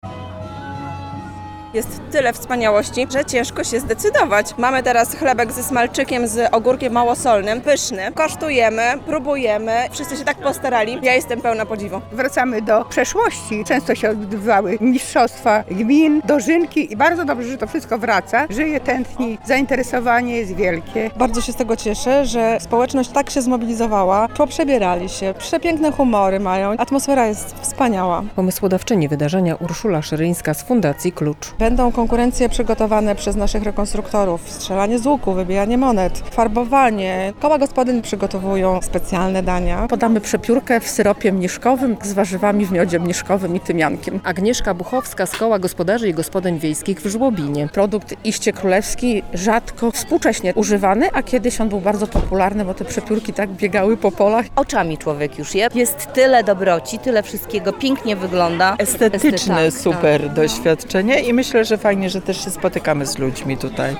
W Berżnikach na Sejneńszczyźnie trwa festiwal "Uczta u Królowej Bony".